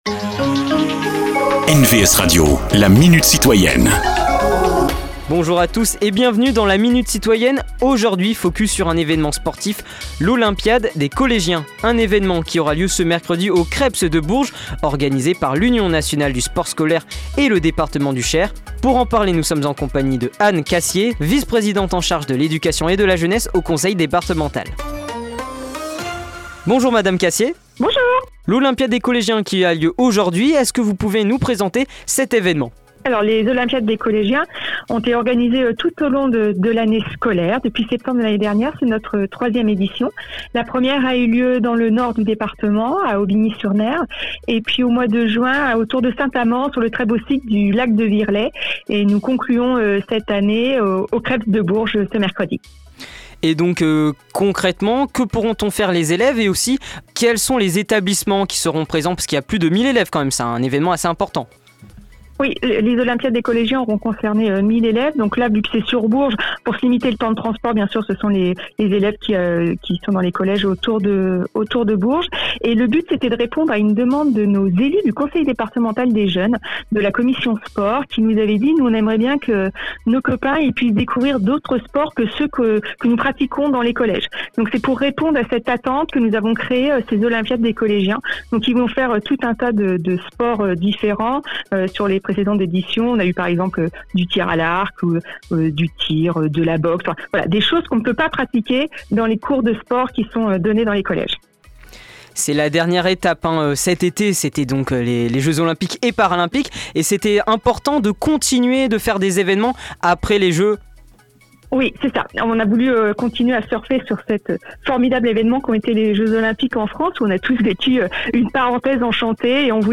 Cette semaine, Anne Cassier, vice présidente en charge de l'éducation et de la jeunesse au conseil départemental du Cher.